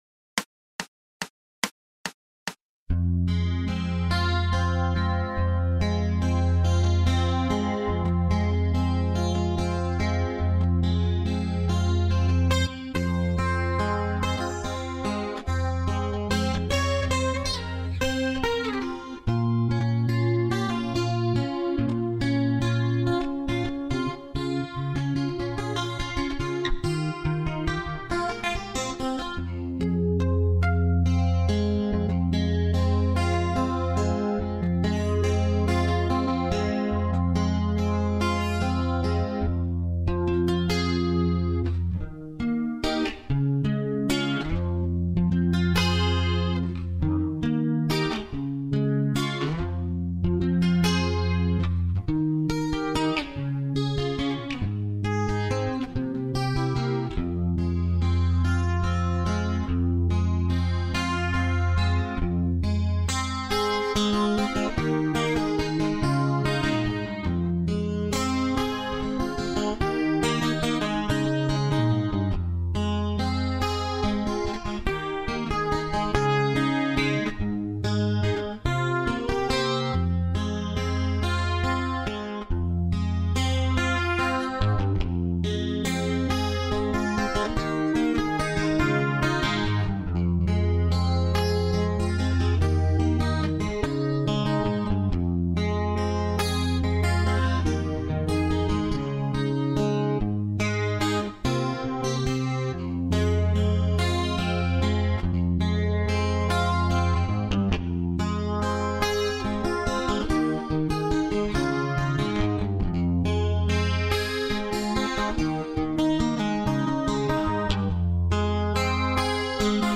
GUITARRA ELÉCTRICA RÍTMICA - ARPEGIO 1 (Descargar)